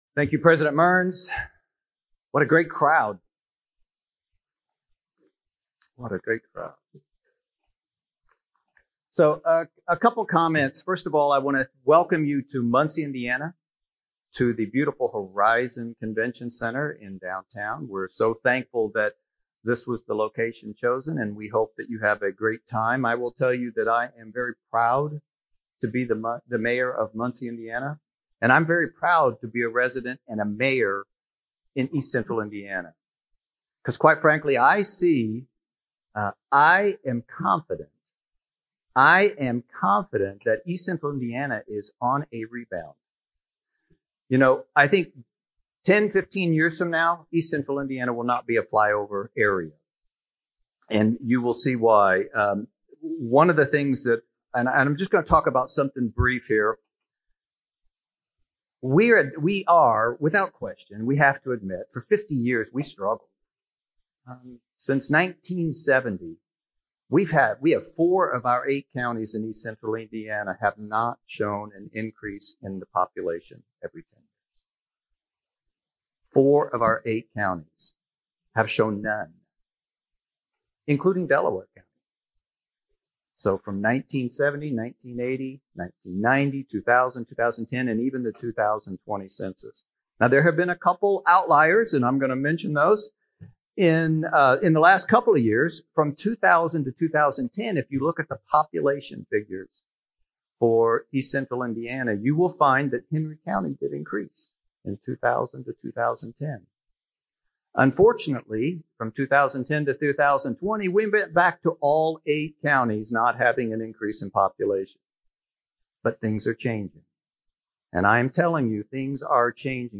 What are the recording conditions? MUNCIE, IN—Tuesday’s Engage Indiana event in Muncie focused on the unique challenges and distinct opportunities for growth of Muncie and the entire East Central Indiana region.